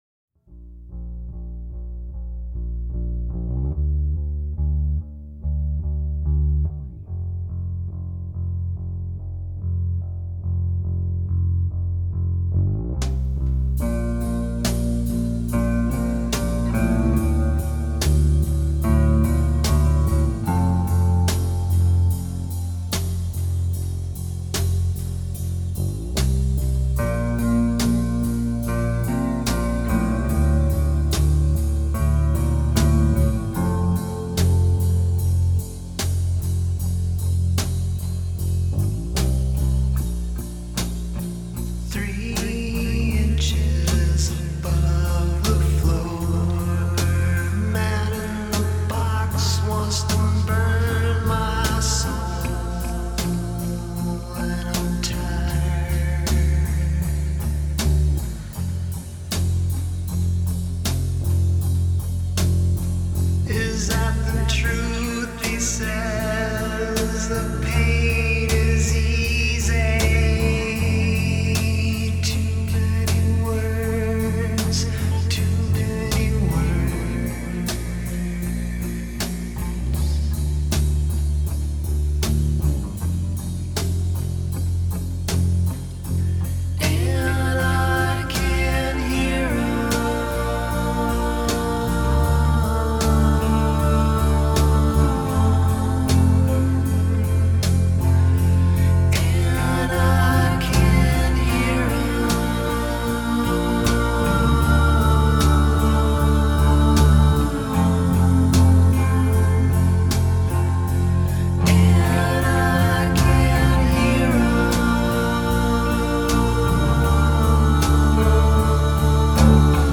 Slowcore Indie Rock